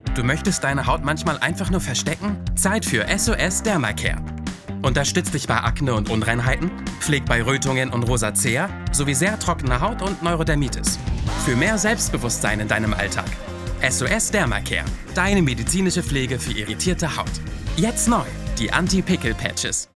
sehr variabel
Mittel minus (25-45)
Trick, Lip-Sync (Synchron)